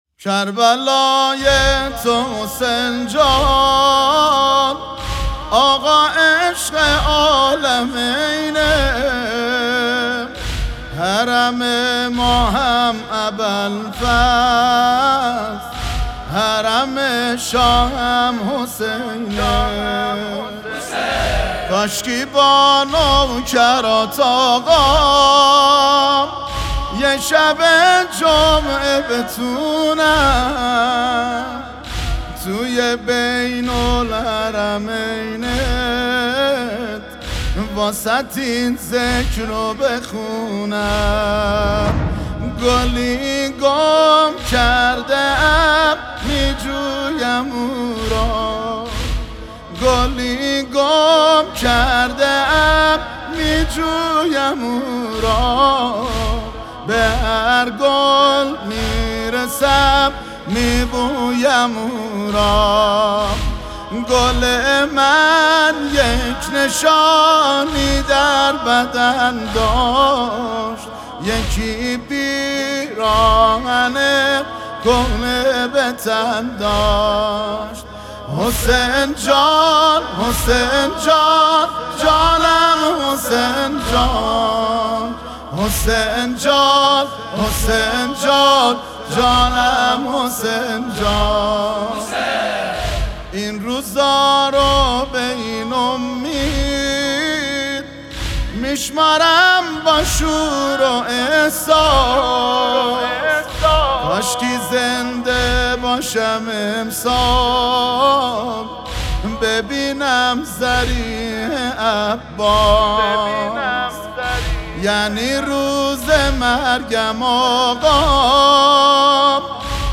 نوحه شیرازی